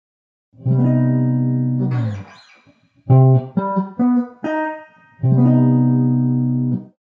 Ad esempio, se scegliamo di usare questi intervalli in sequenza: quinta giusta (7 semitoni), terza maggiore (4 semitoni) e ancora una terza maggiore (4 semitoni), ipotizzando di partire da un C, avremo queste note: C, G, B, Eb [